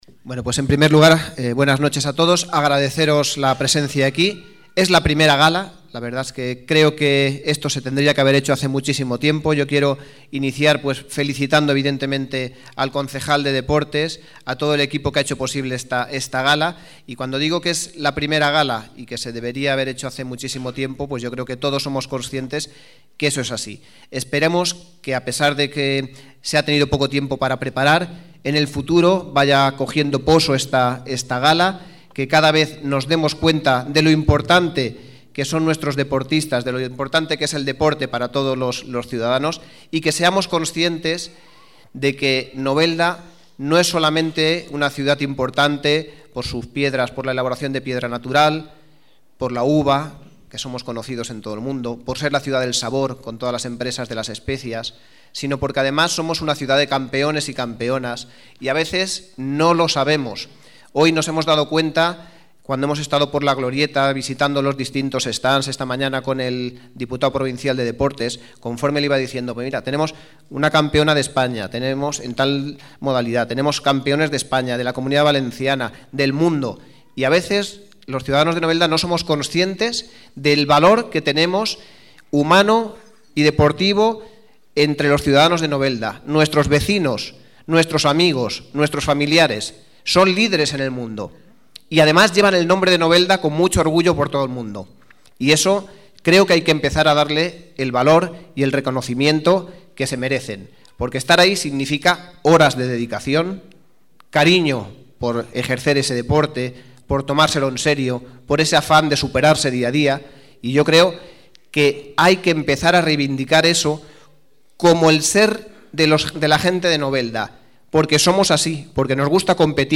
Uno de los actos incluidos en la I Feria del Deporte que se celebró durante el pasado fin de semana fue la Gala del Deporte, con la que se quiso reconocer la labor y méritos logrados por los deportistas noveldenses.
También se dirigió a los asistentes la máxima autoridad de Novelda, Armando Esteve, que aseguró “no somos solamente líderes en piedra, uva o especias sino que también somos una ciudad llena de campeonas y campeones, y quizás no lo sabemos ni somos conscientes de esos valores humanos y deportivos que nos rodean”.